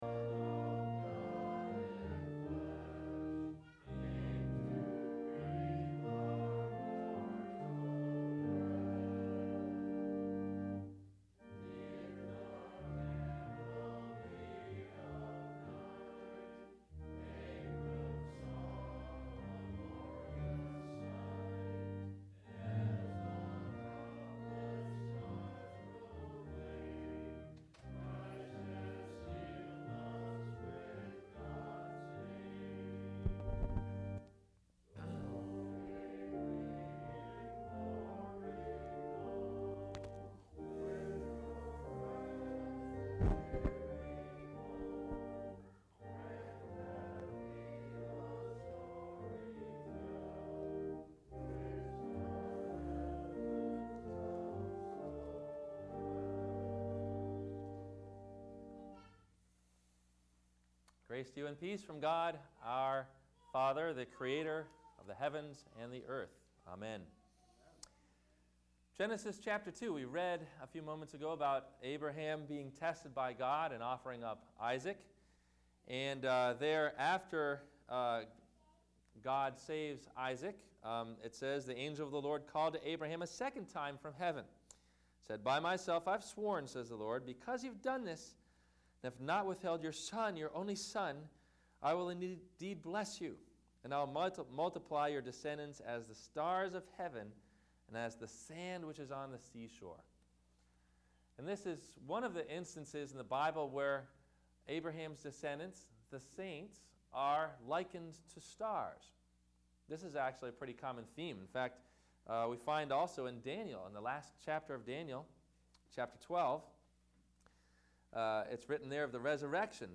Destined Stars – Advent Wed. Week 3 – Sermon – December 15 2010
Advent Wednesday Services Christmas Season